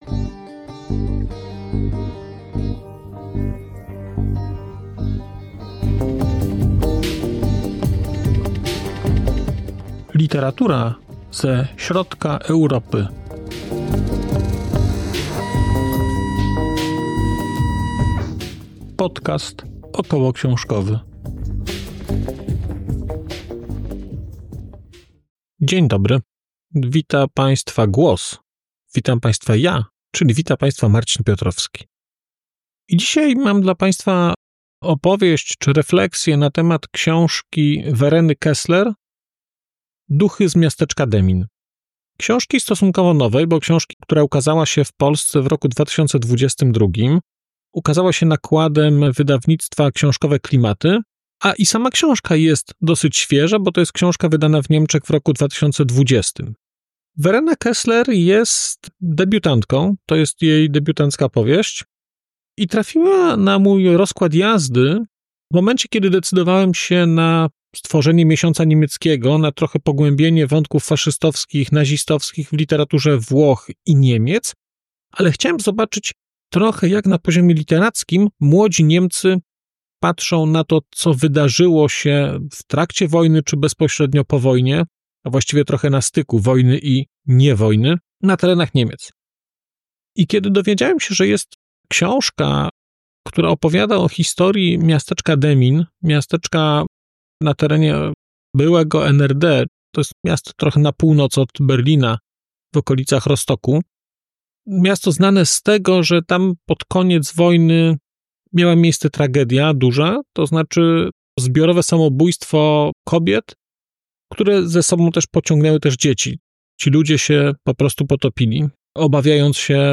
🔧 odcinek zremasterowany: 7.04.2025